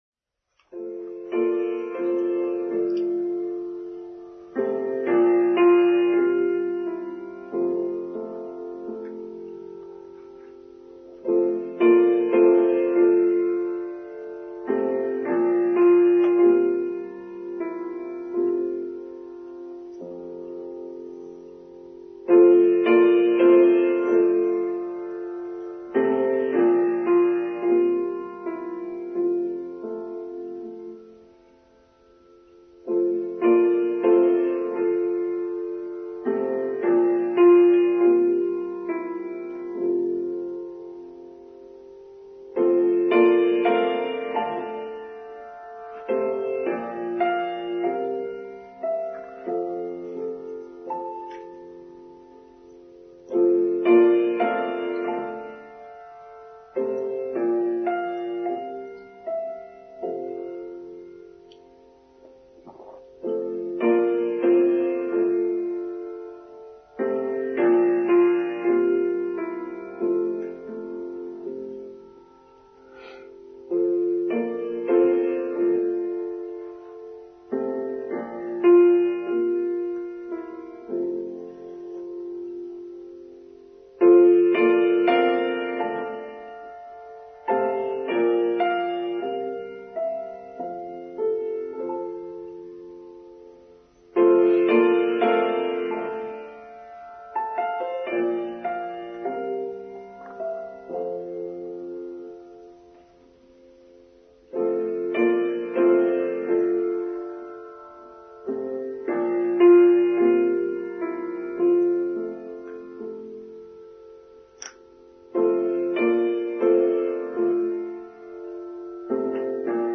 Why Are We Here? Online Service for Sunday 4th September 2022